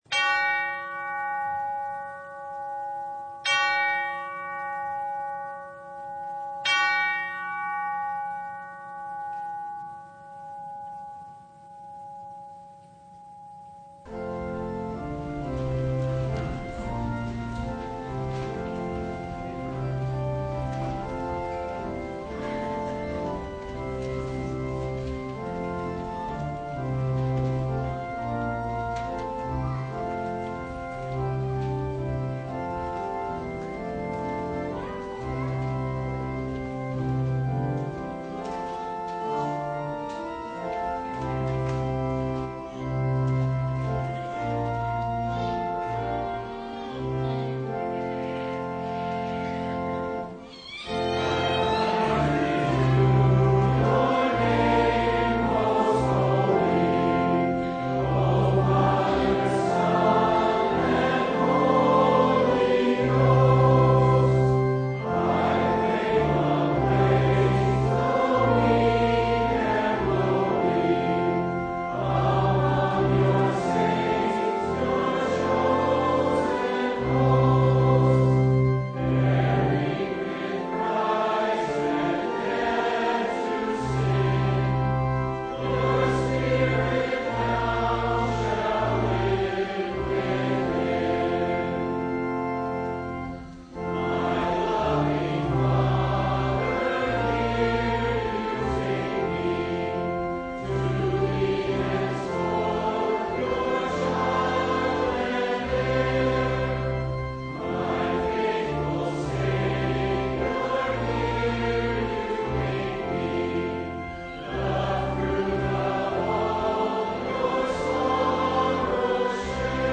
Luke 8:26-39 Service Type: Sunday What is your name?
Download Files Bulletin Topics: Full Service « The Feast of the Holy Trinity (2022) What is Your Name?